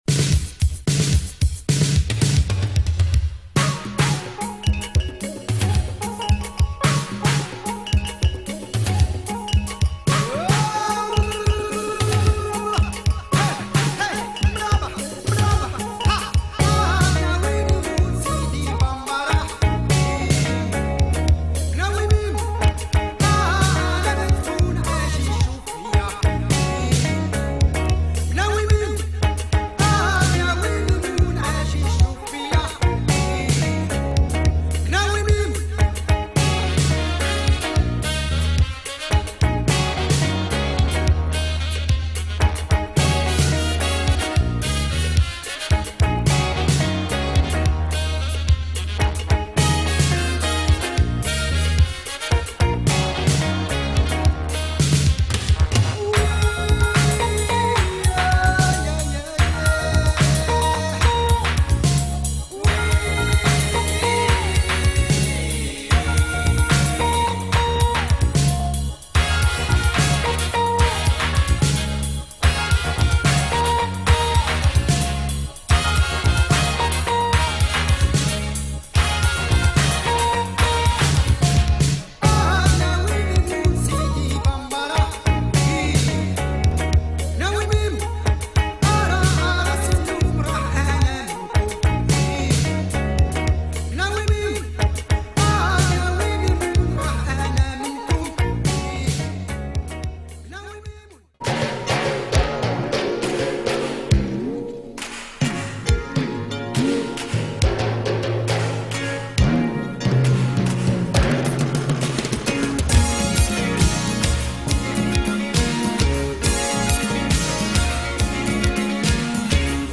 Very rare French Arabic 12' released in the mid 80s.
the Mecca for world fusion.